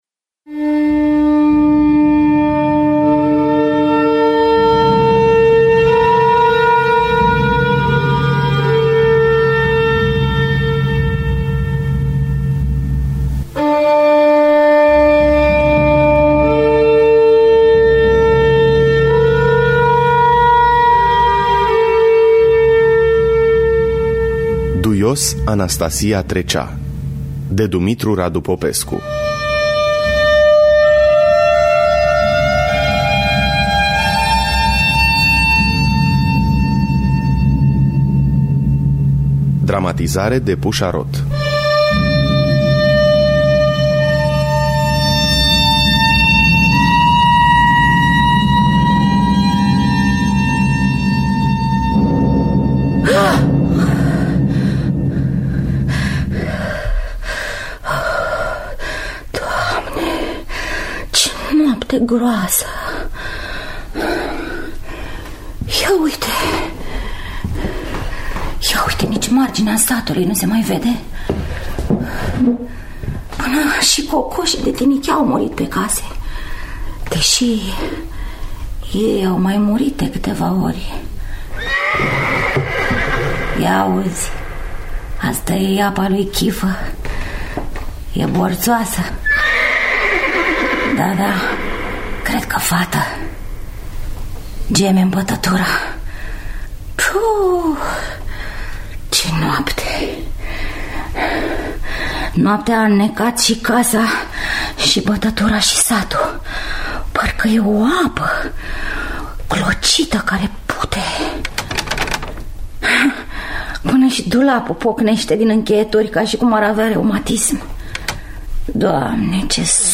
Dramatizare de Puşa Roth.
Efecte percuţie
La vioară